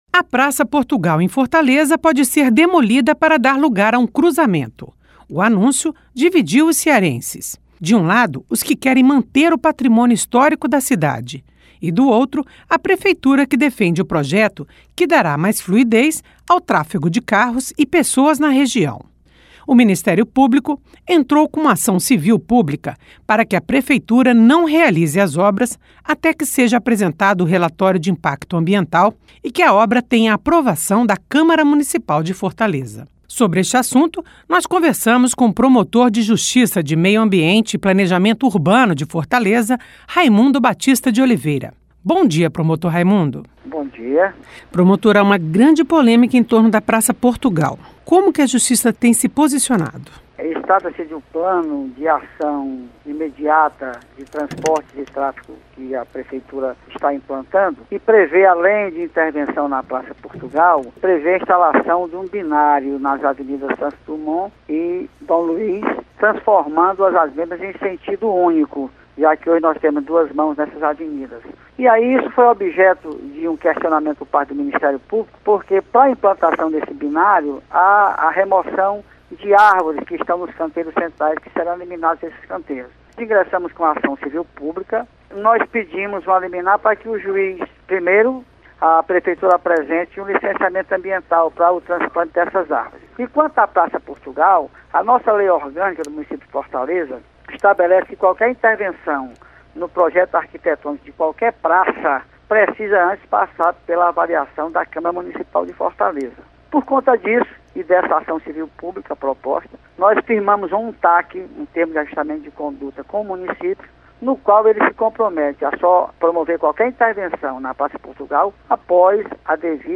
Entrevista com Raimundo Batista de Oliveira, promotor de Justiça de Meio Ambiente e Planejamento Urbano de Fortaleza.